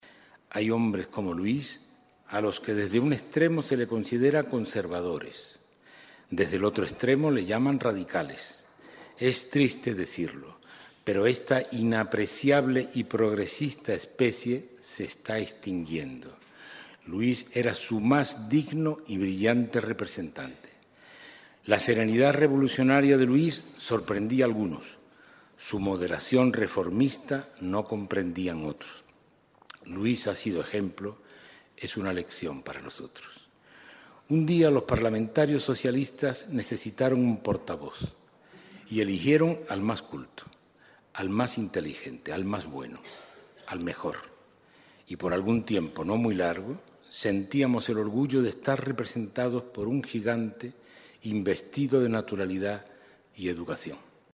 Fragmento del discurso de Alfonso Guerra en el homenaje a Luis Martínez Noval en el Congreso. 16/10/2013